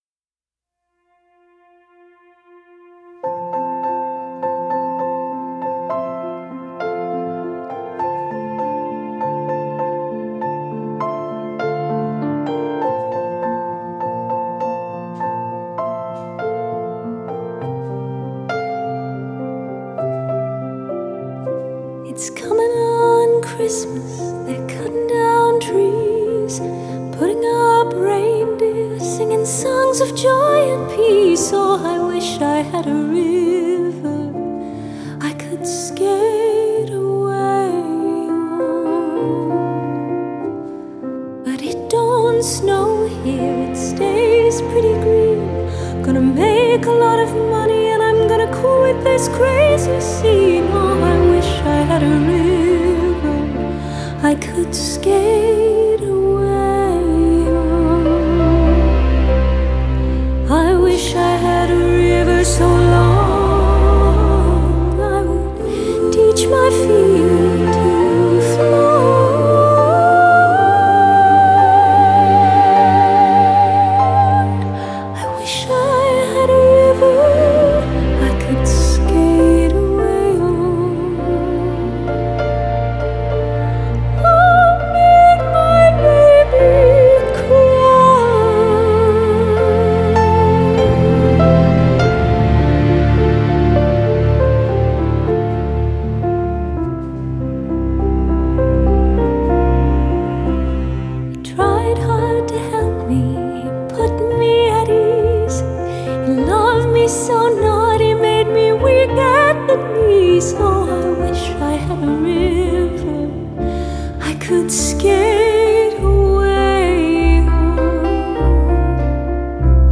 Genre: Classical Crossover, Classical